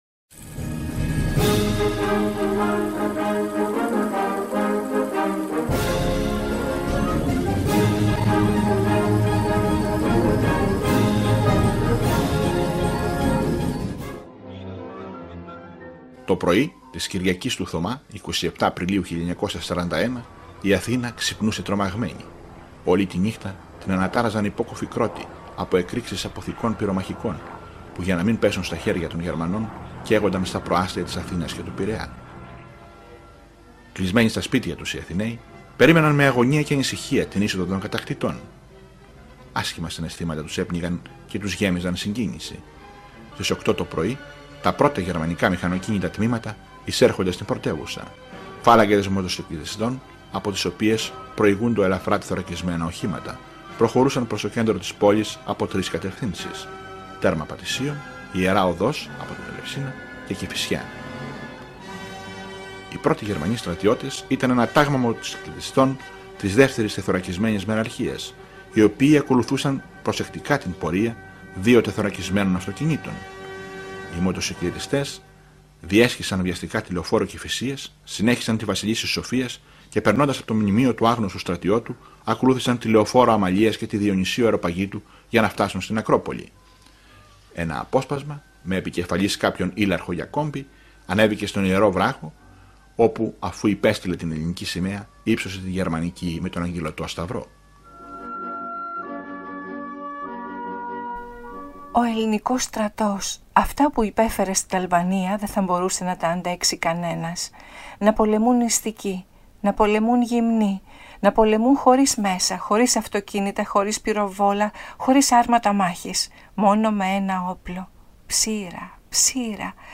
Οι συνθέσεις «φωτίζουν» το λόγο χαρακτηριστικών αποσπασμάτων λογοτεχνικών έργων, επιφανών λογοτεχνών που μεταφέρουν ιδανικά το κλίμα του πολέμου.